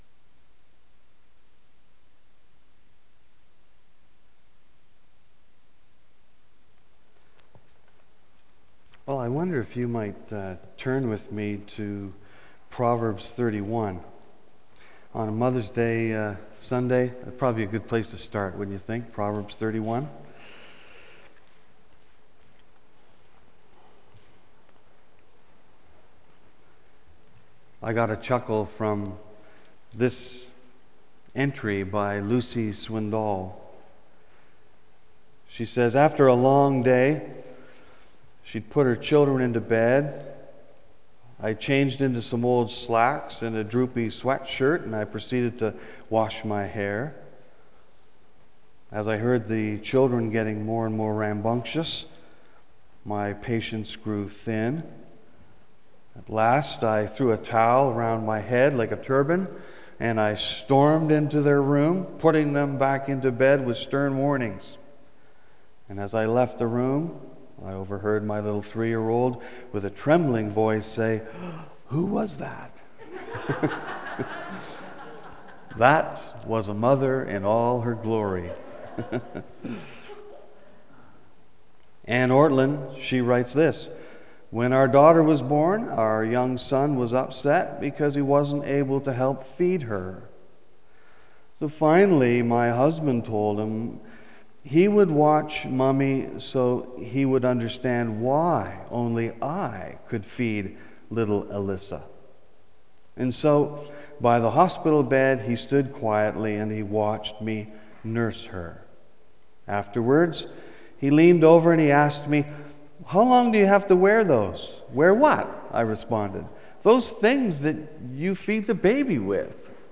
BACK TO SERMON LIST Preacher